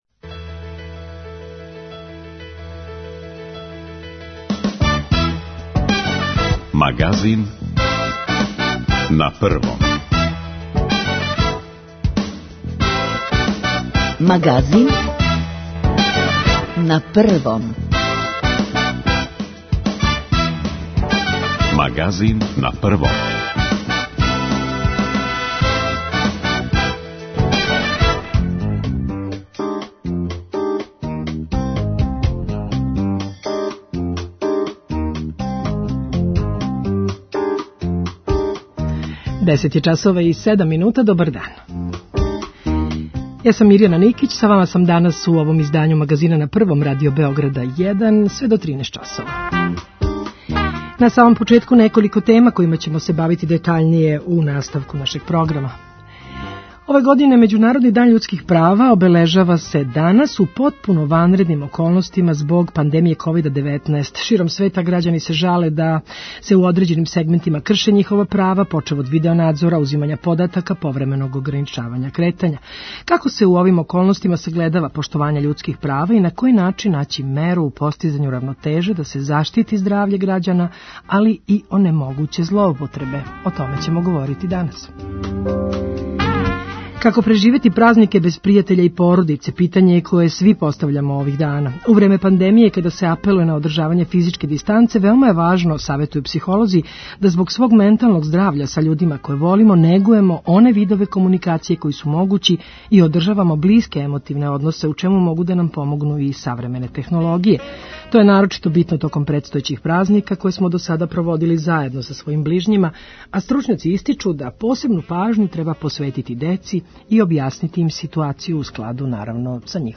Како се у овим околностима сагледава поштовање људских права и на који начин наћи меру у постизању равнотеже да се заштитити здравље градјана, али и онемогуће злоупотребе питања су за нашег саговорника Милана Мариновића, повереника за заштиту података о личности и информације од јавног значаја.